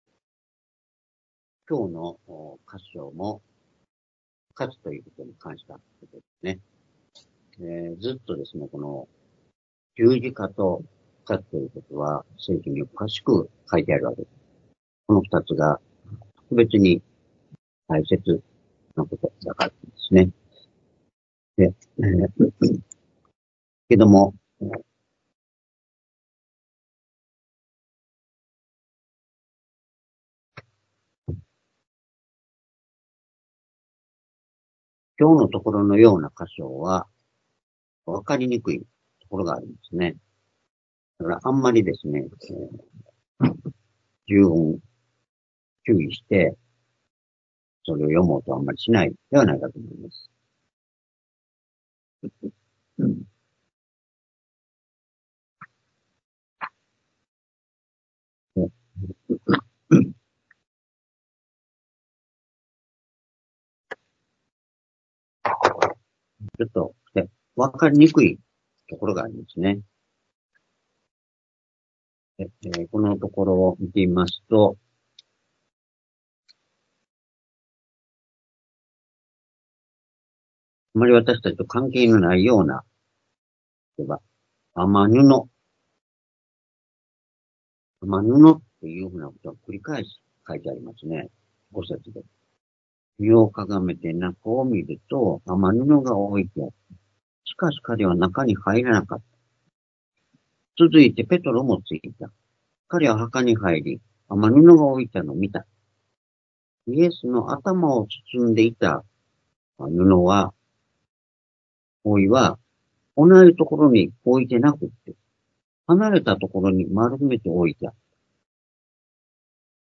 復活を信じる道」ヨハネ福音書20章5～10節-2025年3月16日(主日礼拝)